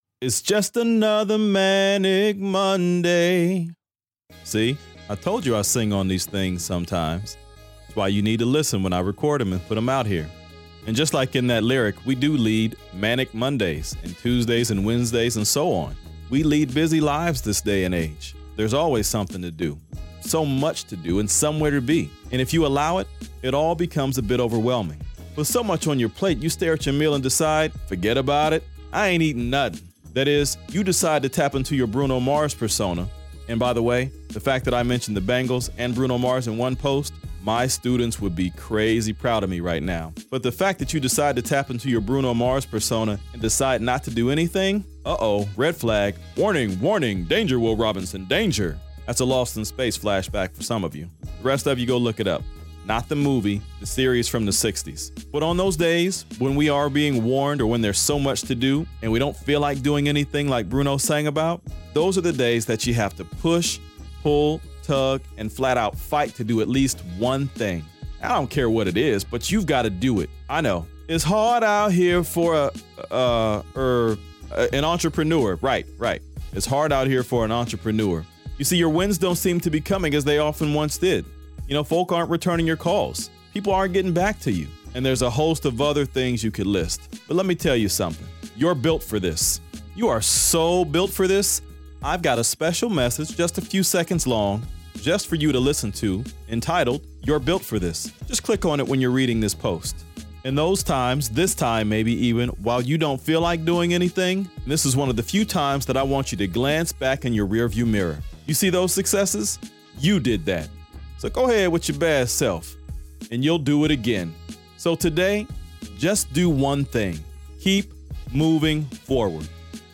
In this post, I sing (seriously, I do) about One Thing: Keep Moving Forward.